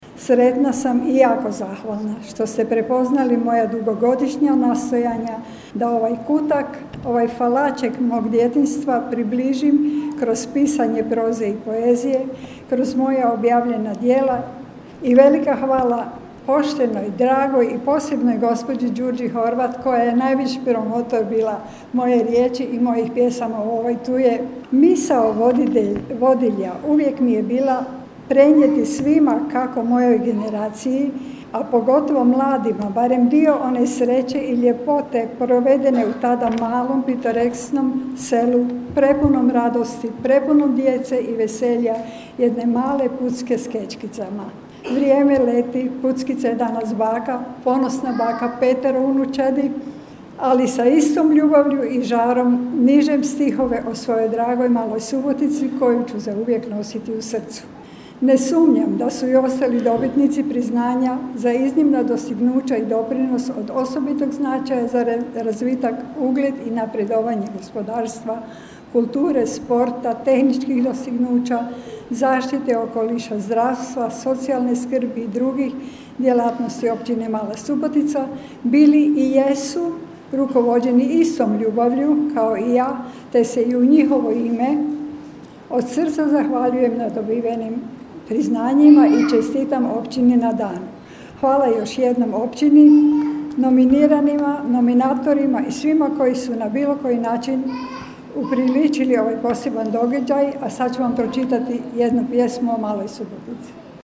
Na svečanoj sjednici Općinskog vijeća Općine Mala Subotica u povodu Dana općine koji su u znaku 30. obljetnice dodijeljene su općinska javna priznanja, a za svoje uspjehe nagrađene su srednjoškolci i studenti.